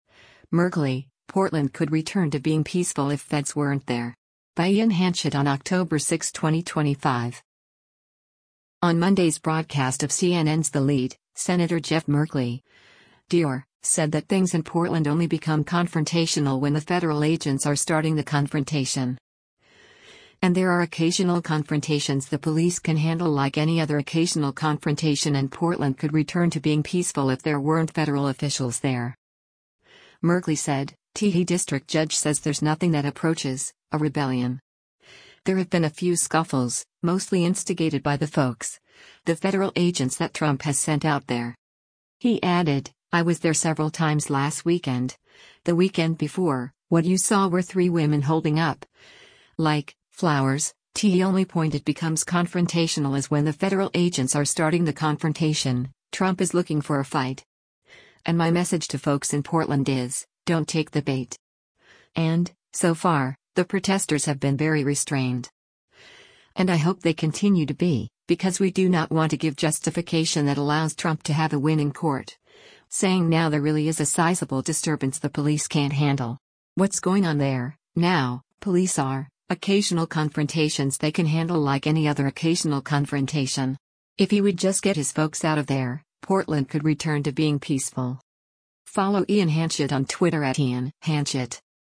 On Monday’s broadcast of CNN’s “The Lead,” Sen. Jeff Merkley (D-OR) said that things in Portland only become confrontational “when the federal agents are starting the confrontation.”